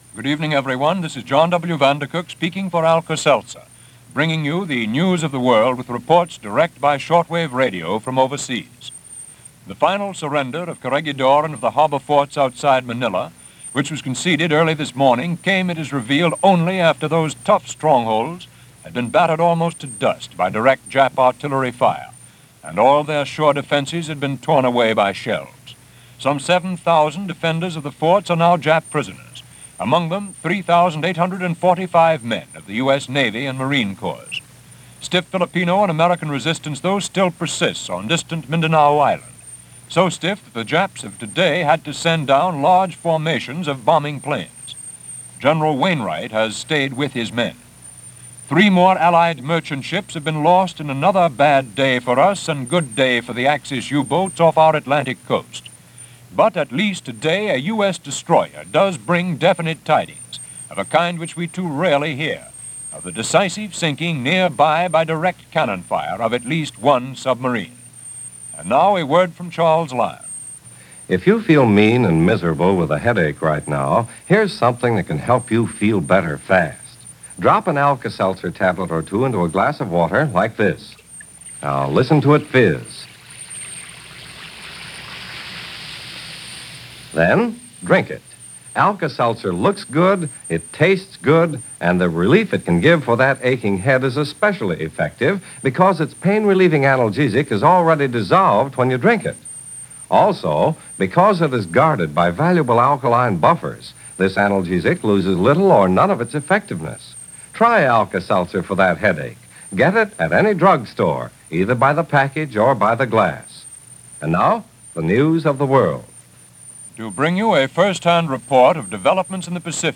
News-May-6-1942.mp3